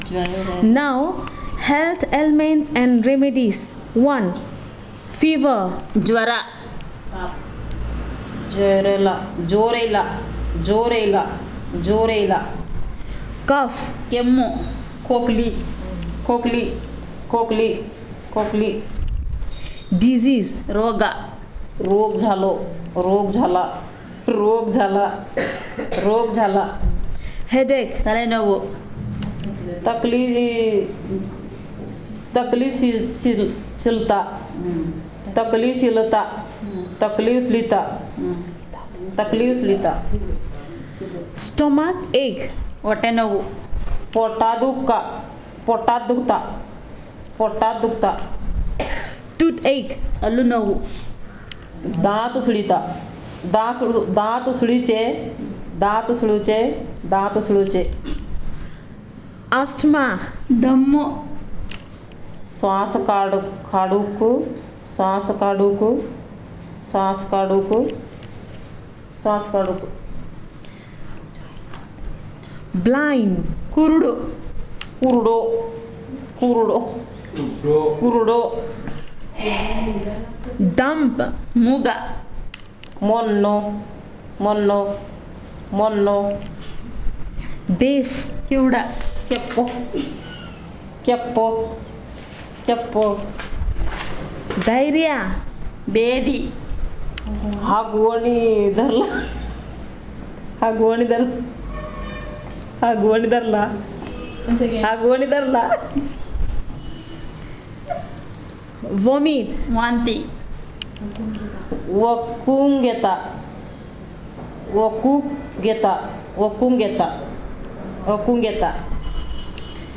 NotesThis is an elicitation of words about health ailments and remedies using the SPPEL Language Documentation Handbook.